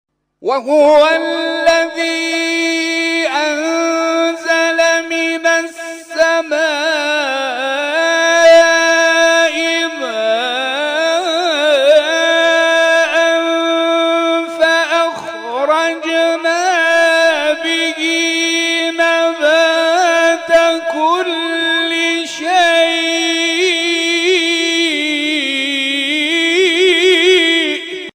فرازهایی از محفل این هفته آستان عبدالعظیم(ع)
گروه جلسات و محافل: محفل انس با قرآن این هفته آستان عبدالعظیم الحسنی(ع) با تلاوت قاریان ممتاز و بین‌المللی کشورمان برگزار شد.